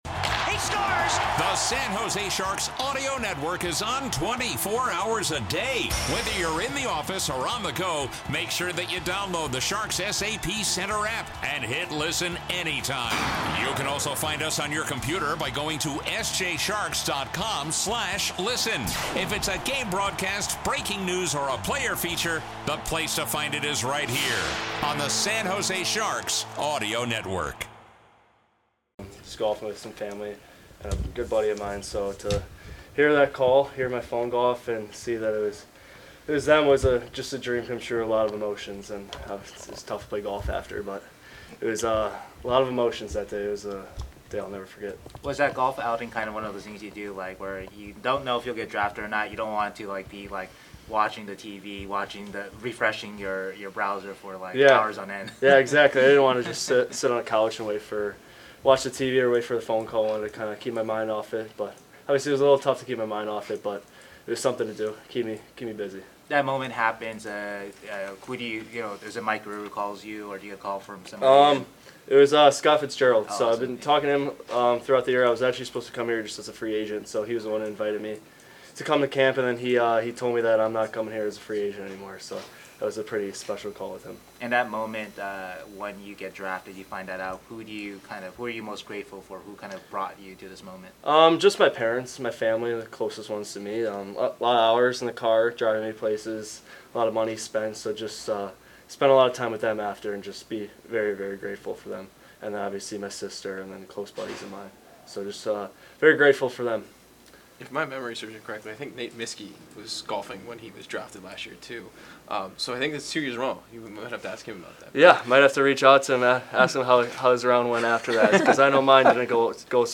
Post Game Comments